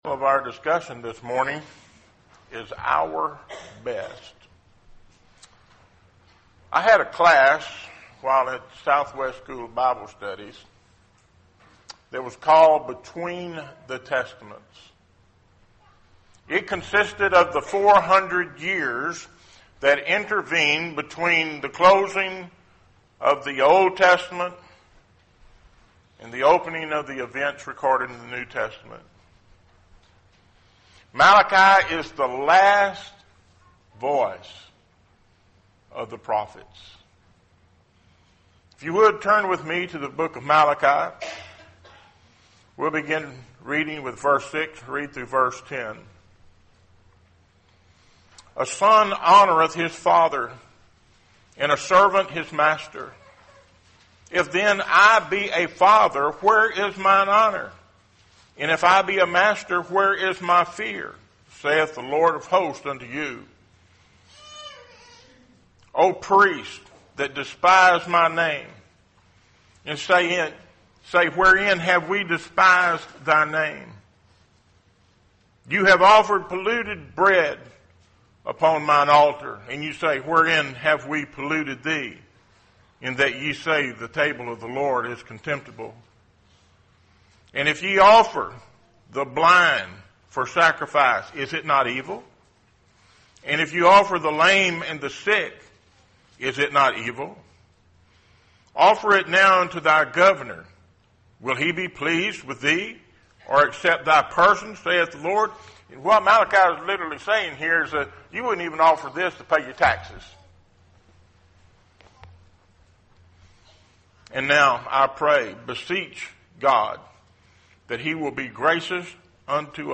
Series: Sermon